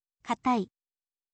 katai